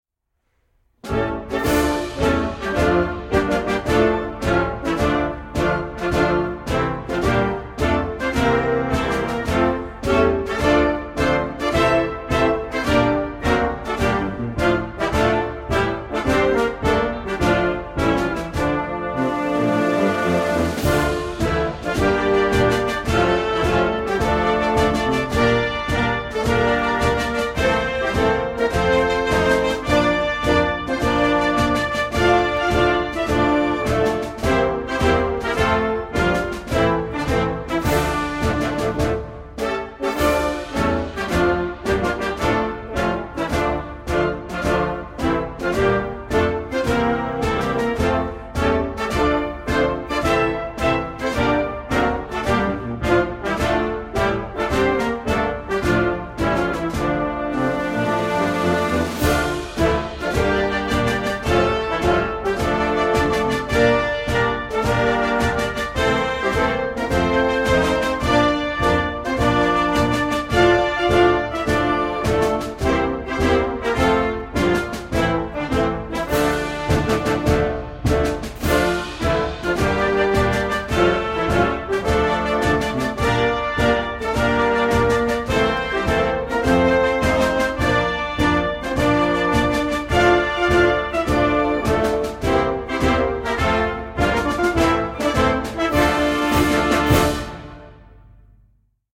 Оркестровая версия